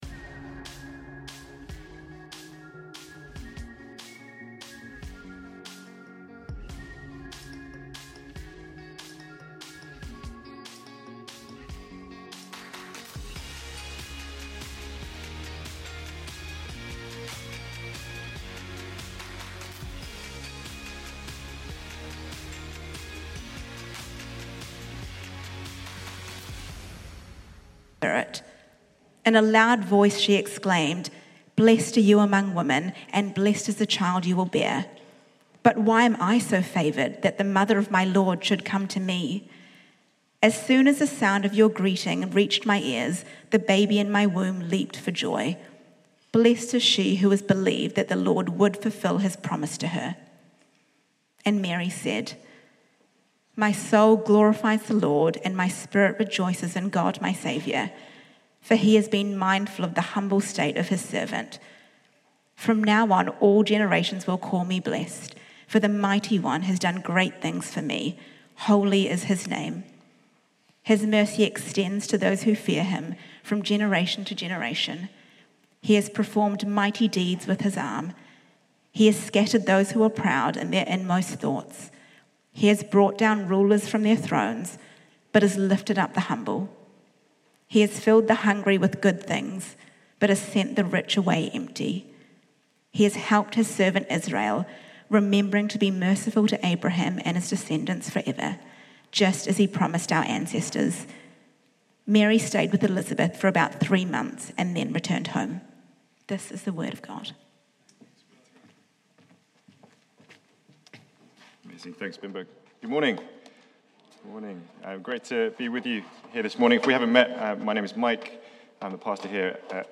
Sunday Sermons - Reality Church London